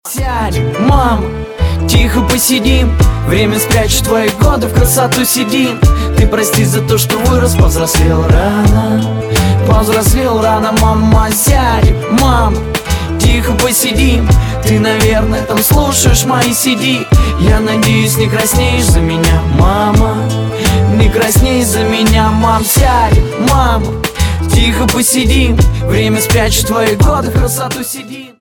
• Качество: 320, Stereo
лирика
душевные
русский рэп